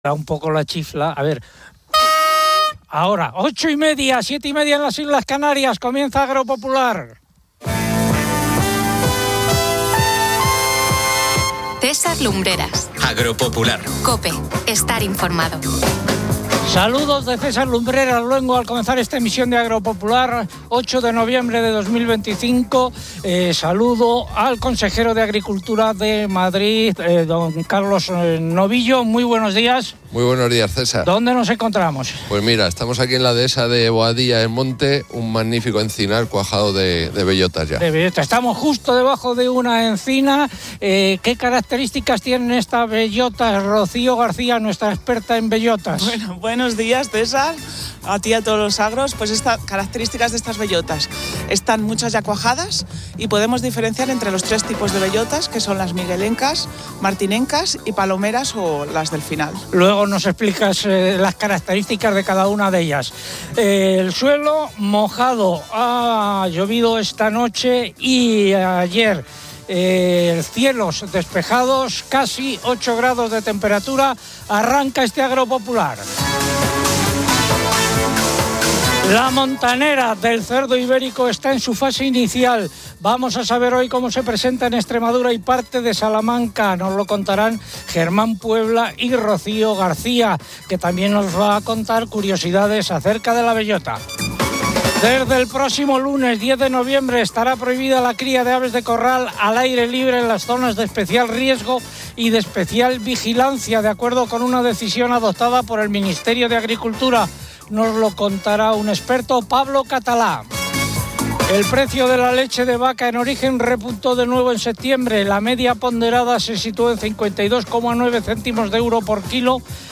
El Consejero de Agricultura de Madrid, Don Carlos Novillo, está presente en la Dehesa de Boadilla del Monte.